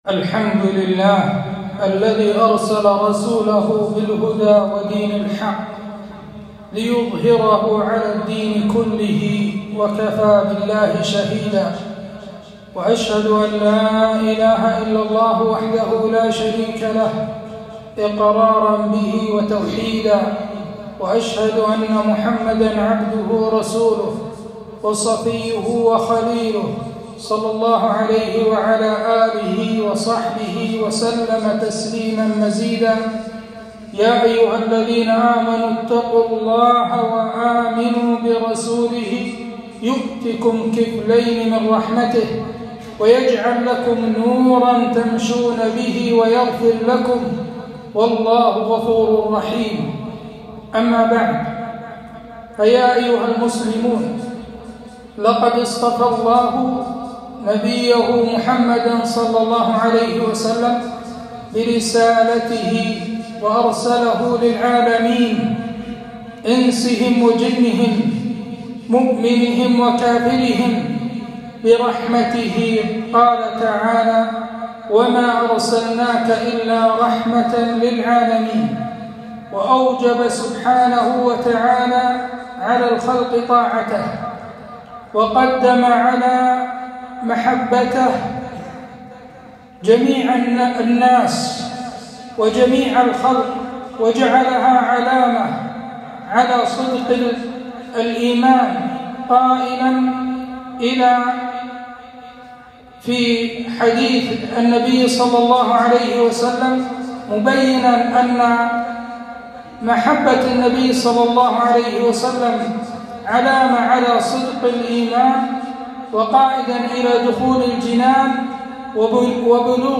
خطبة - محبة الرسول ﷺ بين الاتباع والابتداع